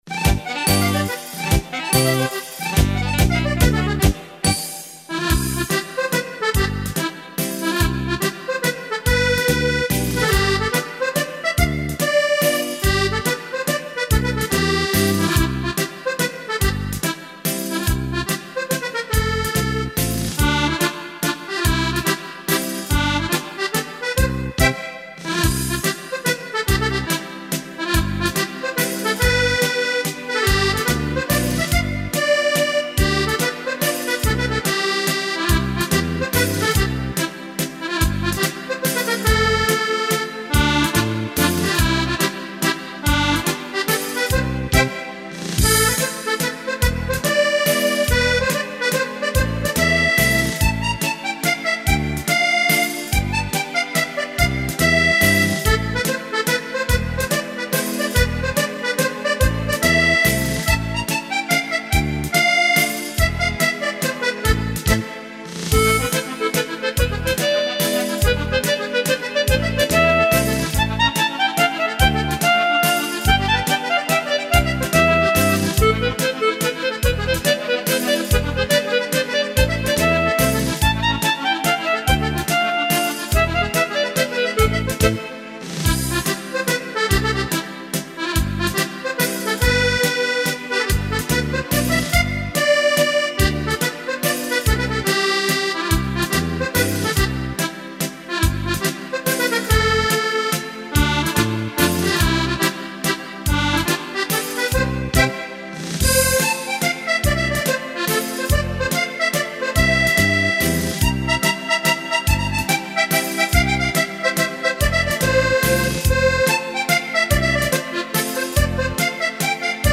Canzoni e musiche da ballo
mazurca